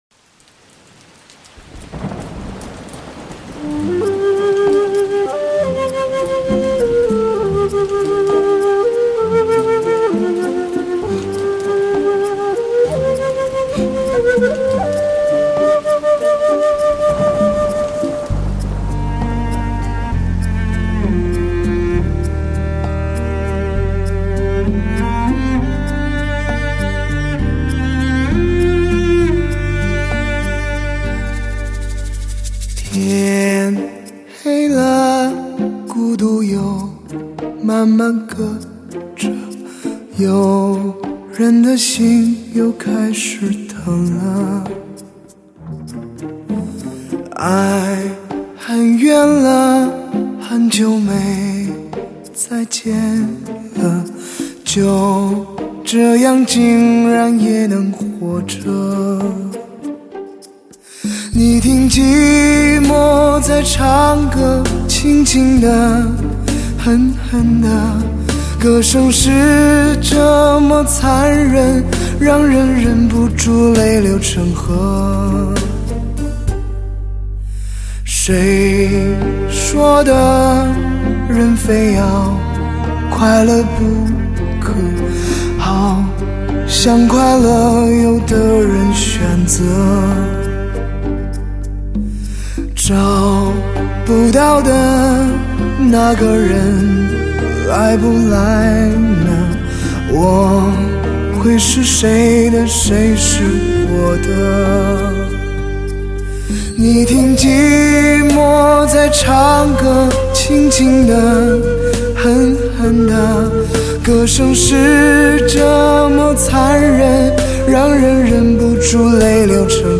史上最具HI-FI震撼的发烧男声
震撼全场的音效，吸引所有目光的美妙靓声
德国黑胶技术+尖端科技K2HD=显示完美监听效果